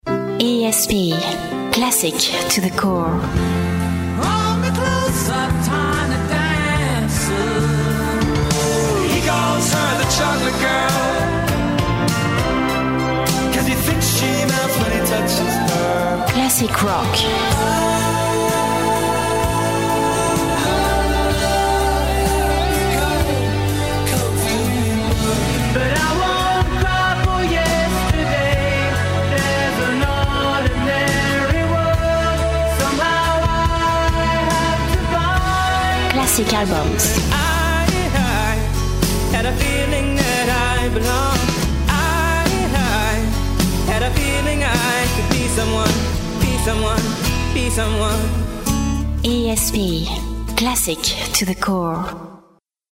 In the late 90s, when DAB licenses were first announced, and after the uphill struggle getting your own FM radio station seemed to be, I thought there was finally a chance to open the radio station I really wanted - a sort of classic rock/genius pop fusion, presented with the sort of eerie European production that was around at the time.